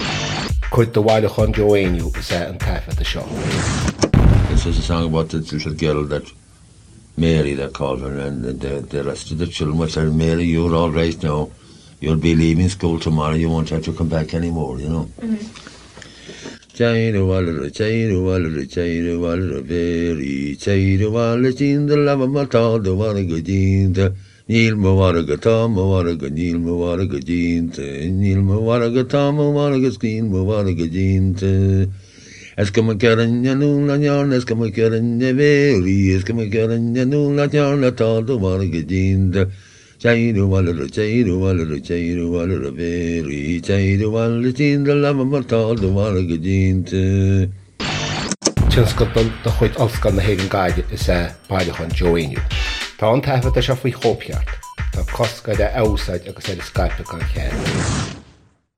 • Catagóir (Category): song.
• Ainm an té a thug (Name of Informant): Joe Heaney.
• Suíomh an taifeadta (Recording Location): University of Washington, United States of America.
• Ocáid an taifeadta (Recording Occasion): interview.
This simple song, sung to a jig-tune, reflects the tough reality of matchmaking, when the girl in question – Mary, in this case – doesn’t seem to have had much of a voice in the decision.